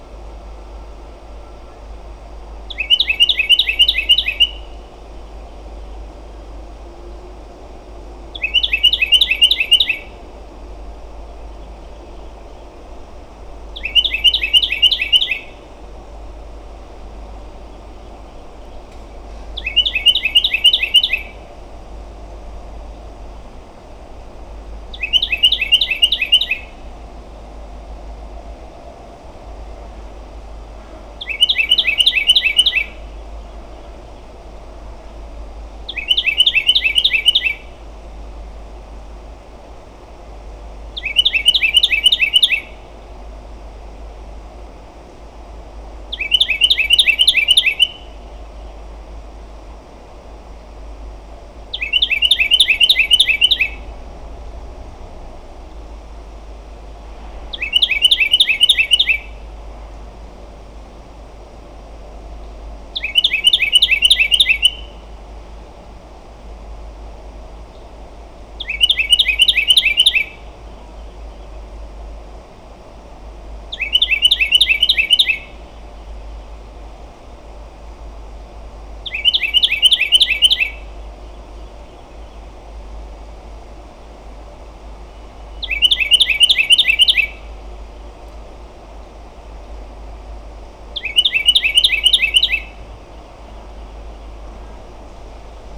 As I was walking around, I could hear Carolina Wrens calling and singing. Some songs sounded just like this:
Carolina Wrens aren’t as loquacious as Brown Thrashers.
carolina-wren-early.wav